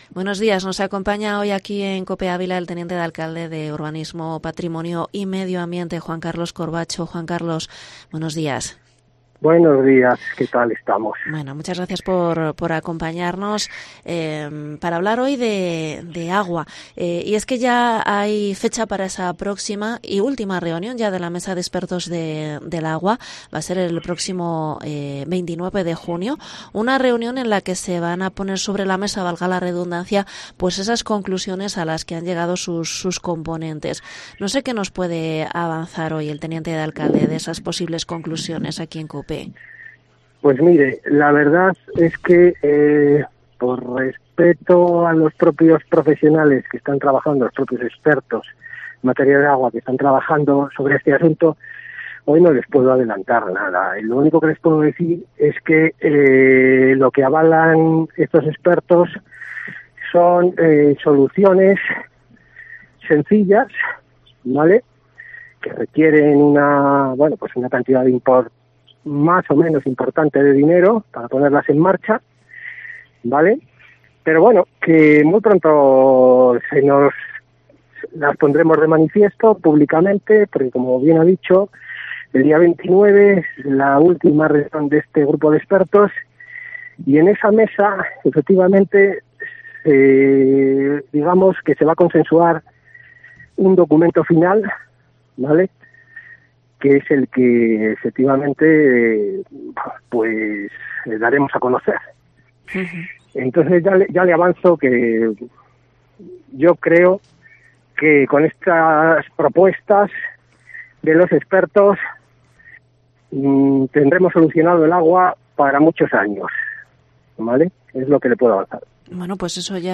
Entrevista al teniente de alcalde, Juan Carlos Corbacho sobre la Mesa del Agua